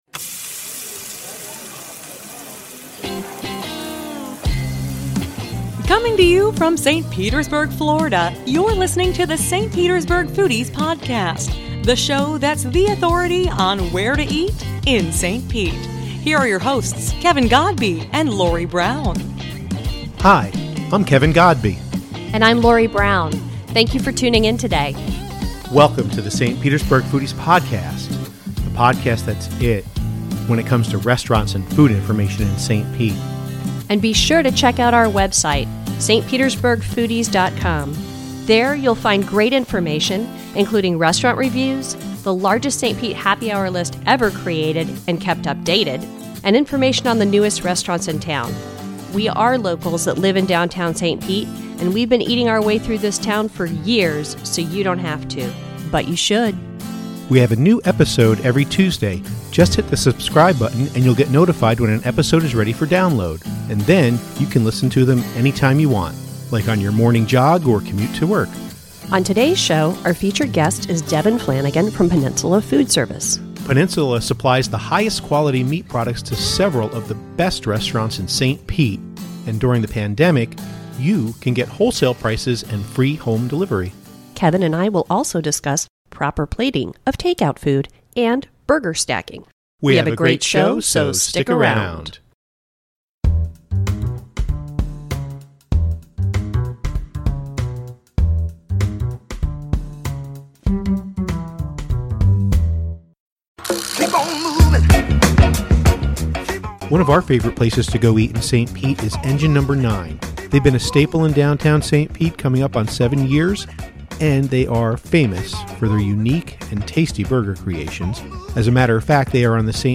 The St. Petersburg Foodies Podcast features interviews with chefs, restaurateurs, sommeliers, bartenders, and more, and covers the burgeoning food scene in St. Petersburg, Florida.